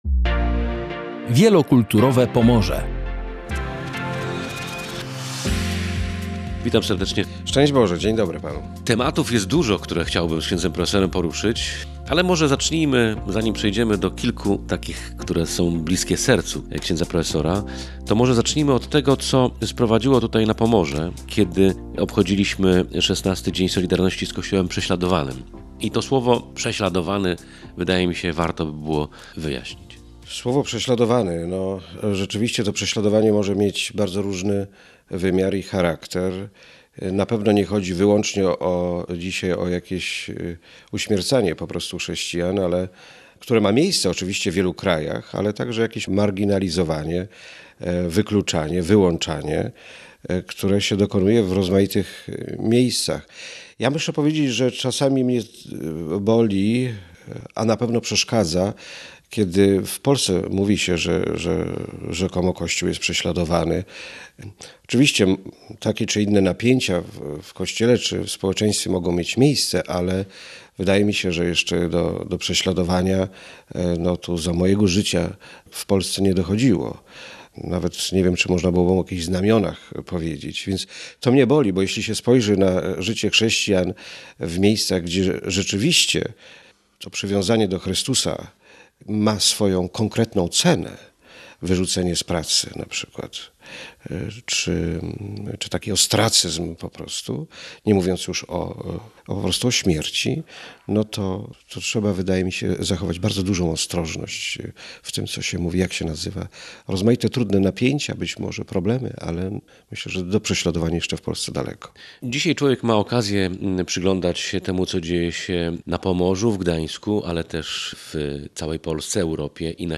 W „Wielokulturowym Pomorzu” rozmawiamy o solidarności z kościołem prześladowanym, o drogach i bezdrożach wiary w Europie i na świecie. A także o akceptacji inności i szacunku do drugiego człowieka.